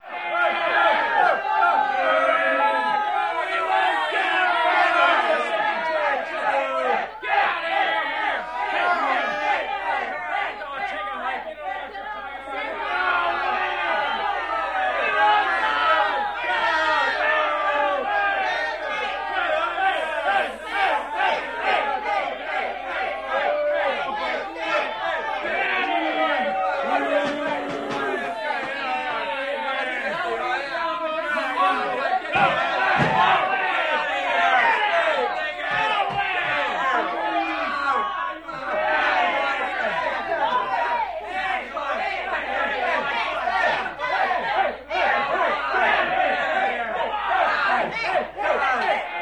Angry Crowd Shouting and Yelling